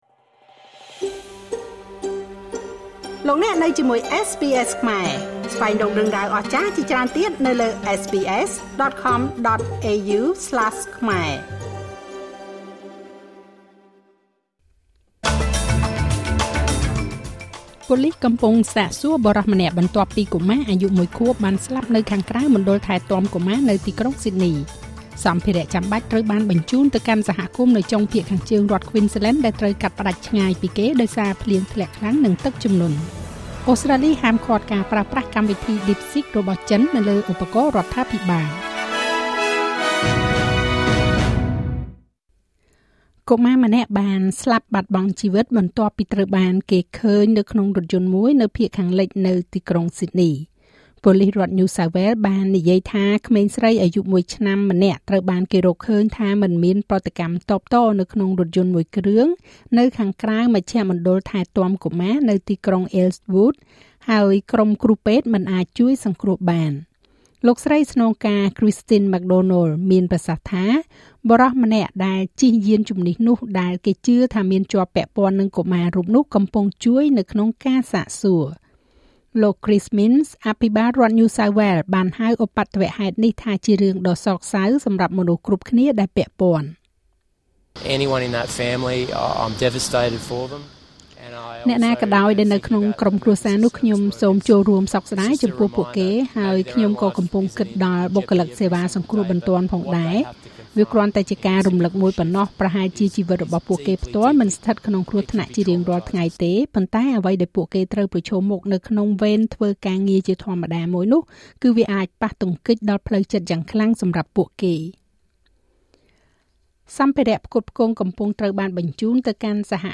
នាទីព័ត៌មានរបស់SBSខ្មែរ សម្រាប់ថ្ងៃពុធ ទី៥ ខែកុម្ភៈ ឆ្នាំ២០២៥